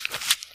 High Quality Footsteps
STEPS Newspaper, Sneak 04.wav